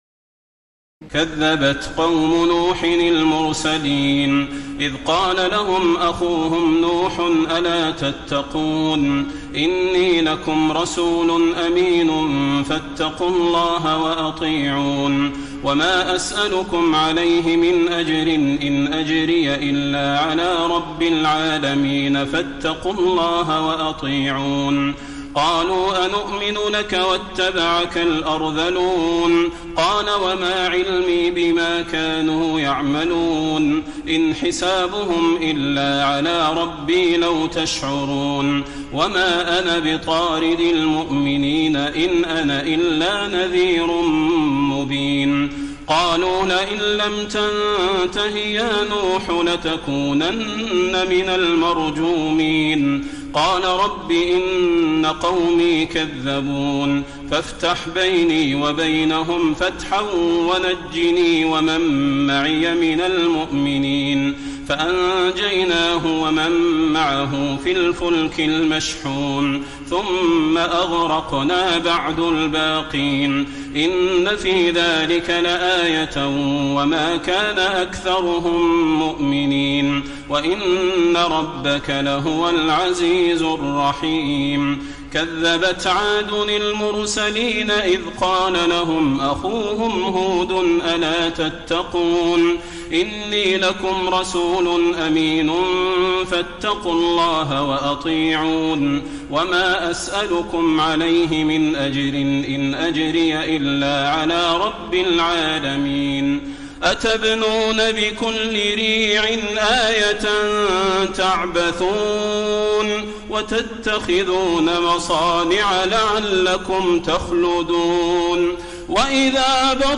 تراويح الليلة الثامنة عشر رمضان 1433هـ من سورتي الشعراء (105-227) والنمل (1-53) Taraweeh 18 st night Ramadan 1433H from Surah Ash-Shu'araa and An-Naml > تراويح الحرم النبوي عام 1433 🕌 > التراويح - تلاوات الحرمين